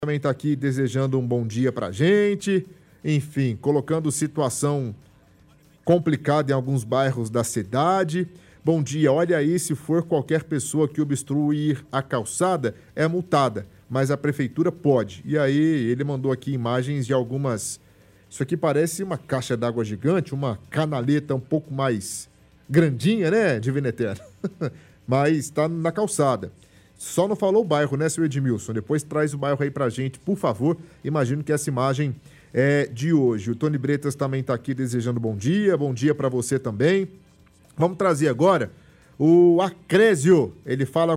– Ouvinte reclama de materiais que estão em cima de passeio, dificultando passagens dos pedestres no local.